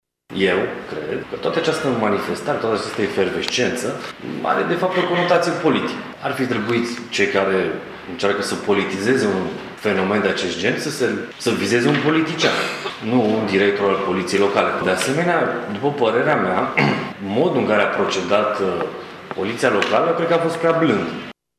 Consilierul PSD, Olimpiu Sabău Pop, a spus că măsurile luate de poliţie trebuiau să fie mai dure deoarece cetăţenii nu pot lua în mâna lor aplicarea legii: